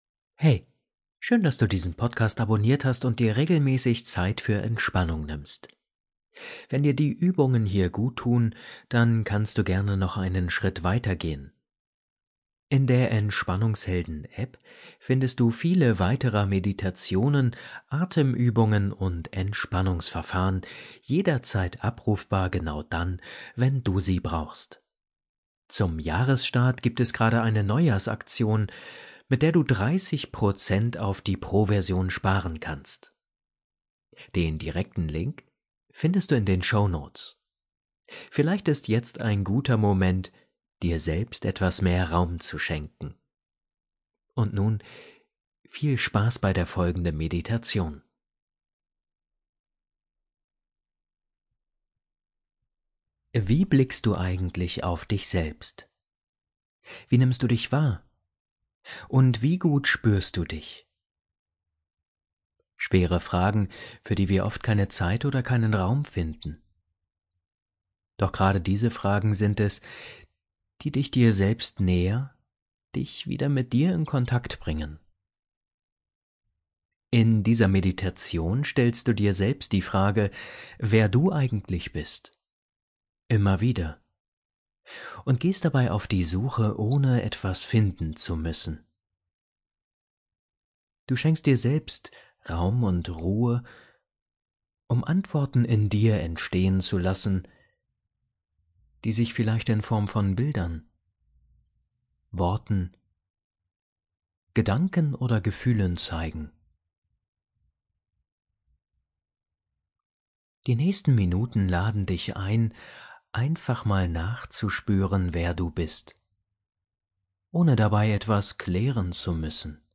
Wer bin ich wirklich? – Achtsame Meditation zur Selbstbegegnung ~ Entspannungshelden – Meditationen zum Einschlafen, Traumreisen & Entspannung Podcast